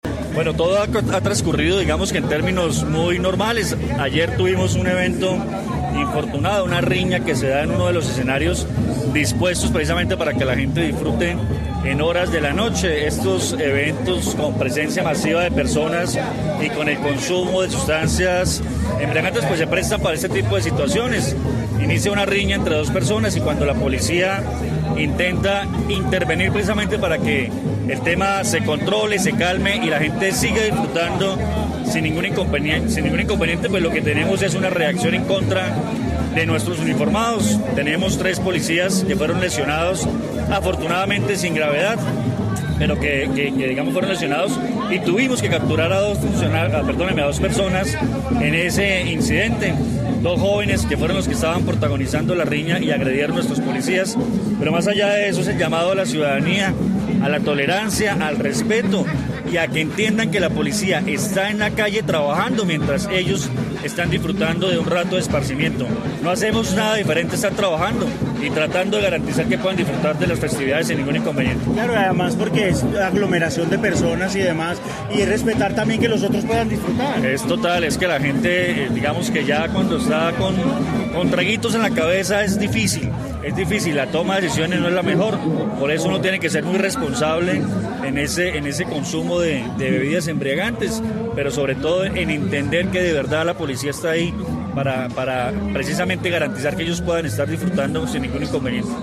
Coronel Luis Fernando Atuesta, comandante Policía, Quindío